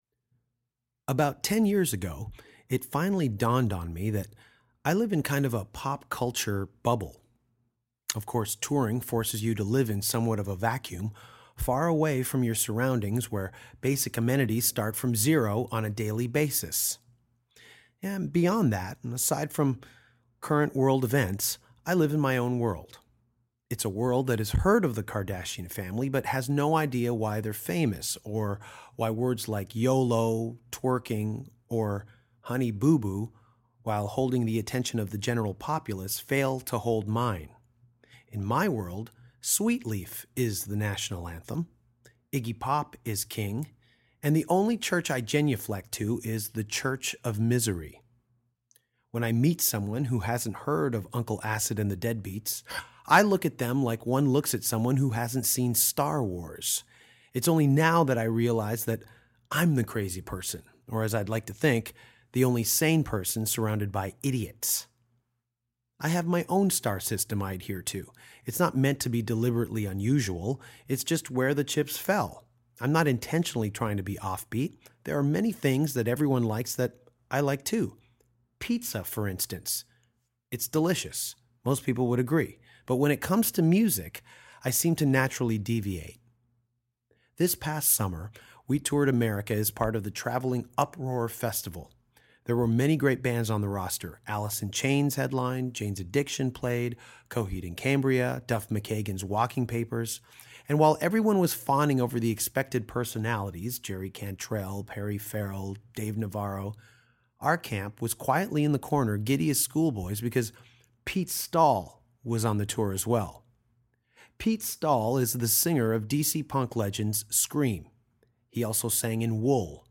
for a great discussion on Iggy Pop, singing on “Never Too Loud”, Nick Raskulinecz, Rancho de la Luna, Ian Svenonius and city mayors.